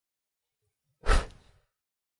浇灭火柴
描述：将比赛拉下来。